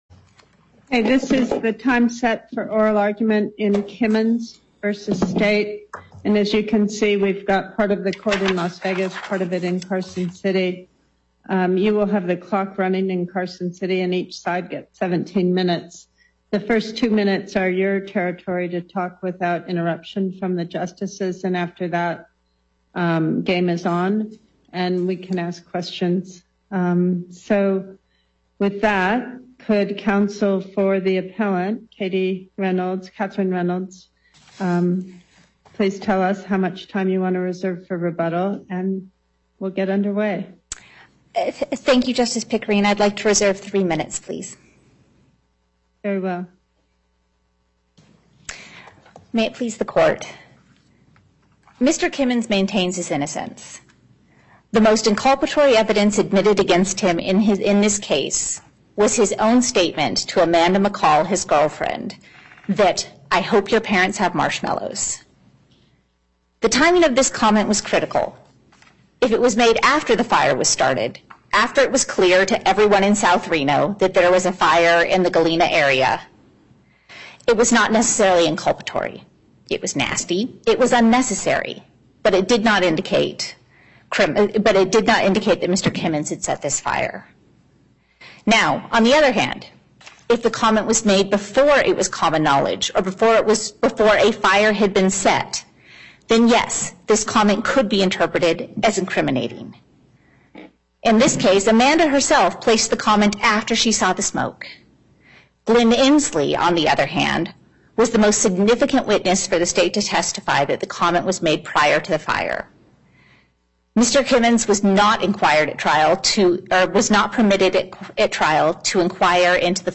Justice Pickering presiding